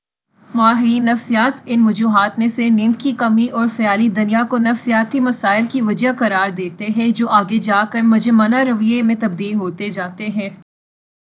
deepfake_detection_dataset_urdu / Spoofed_TTS /Speaker_04 /101.wav